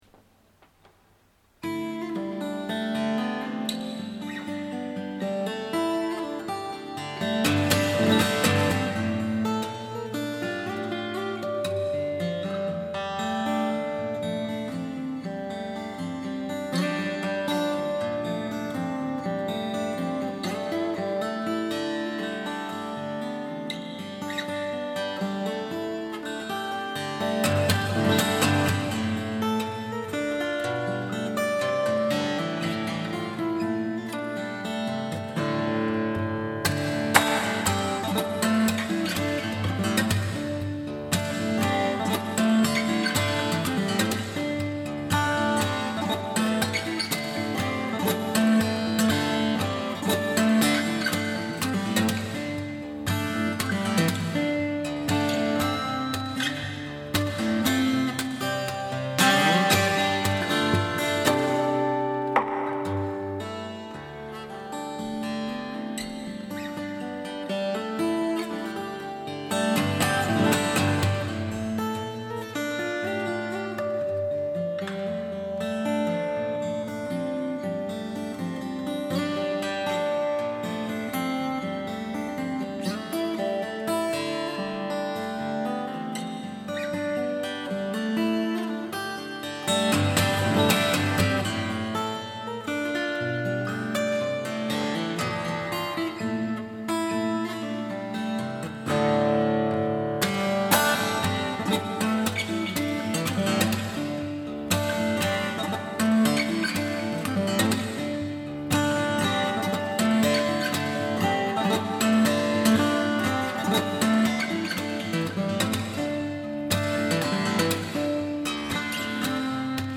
こういう曲になるとボディが少し大きい方がいい感じですね。 この複雑な音でもバランスが取れているのはスプルースですね。
エフェクトをかけてからＭＰ３にｉＴｕｎｅｓで変換しました。
家でソファに座って録画とか録音して，ノートパソコンで簡単に編集できてこのお値段はすごいです。